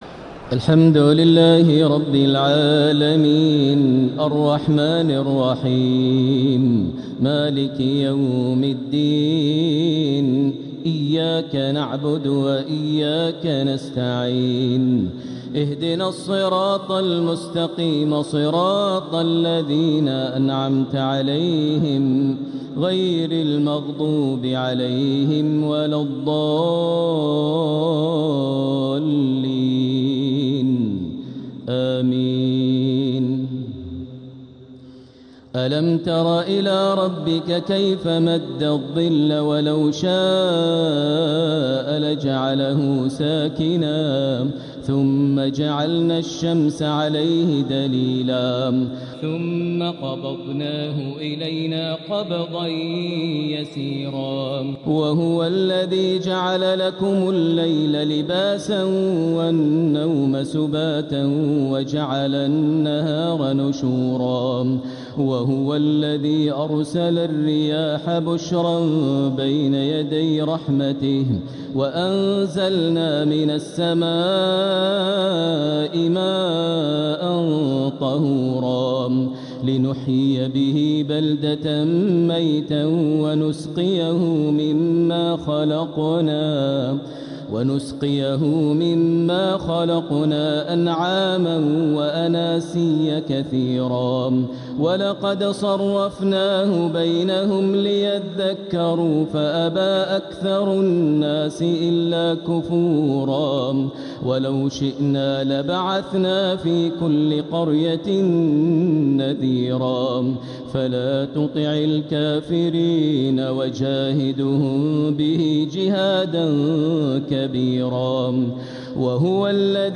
تهجد ليلة 22 رمضان 1447هـ من سورتي الفرقان (45-77) و الشعراء (1-104) | Tahajjud 22nd night Ramadan1447H Surah Al-Furqan and Al-Shua’ara > تراويح الحرم المكي عام 1447 🕋 > التراويح - تلاوات الحرمين